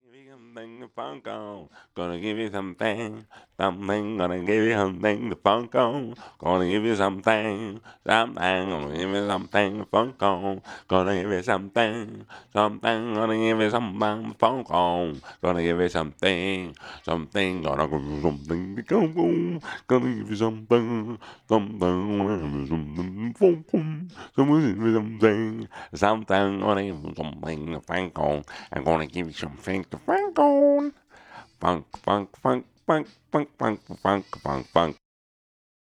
DF_107_E_FUNK_VOX_04 .wav